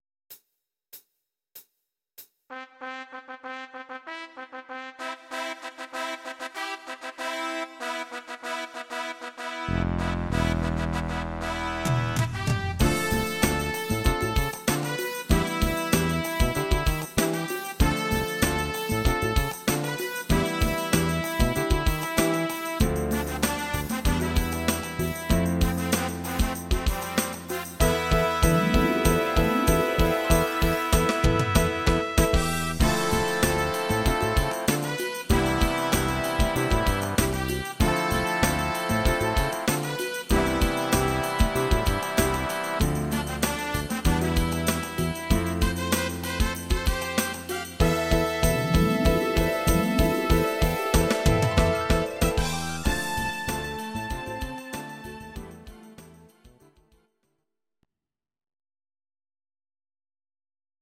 Please note: no vocals and no karaoke included.
Your-Mix: Instrumental (2073)